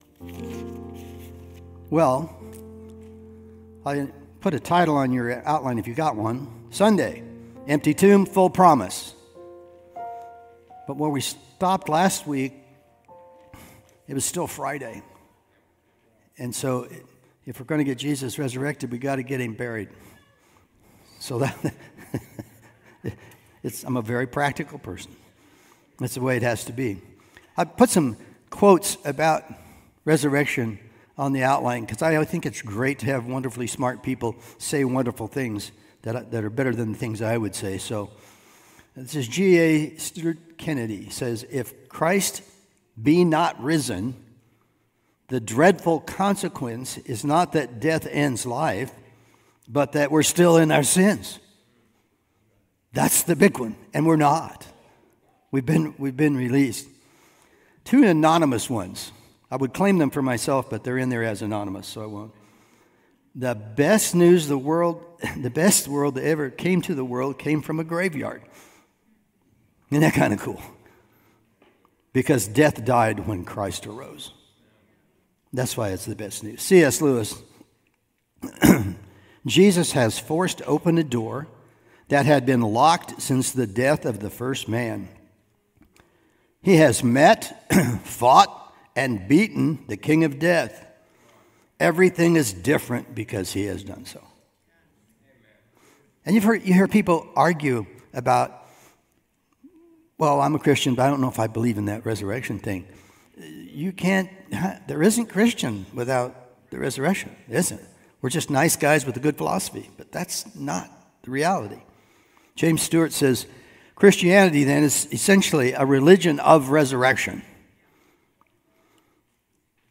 Holiday Sermons